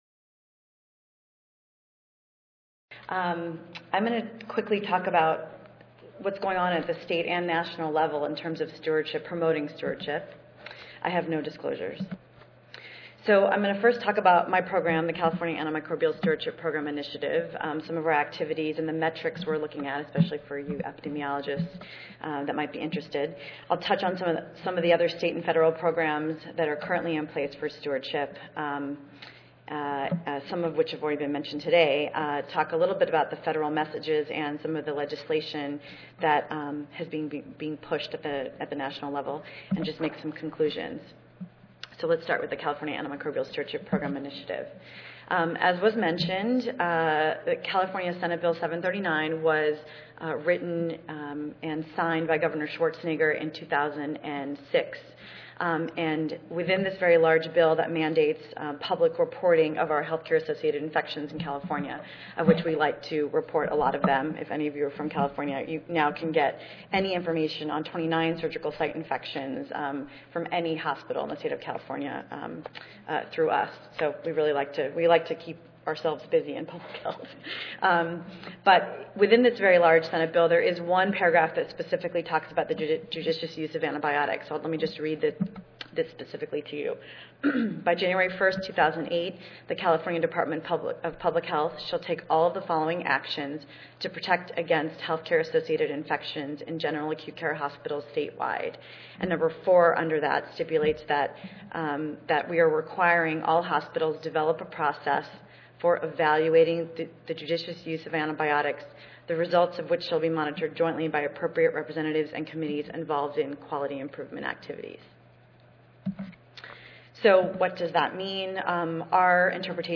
3356.0 Invited Session: From Ranches and Hospitals to the Home: Policy Development for Responsible Antibiotic Use Across the Spectrum Monday, October 29, 2012: 2:30 PM - 4:00 PM Oral Continued availability of effective antimicrobials is vital for the protection of human and animal health.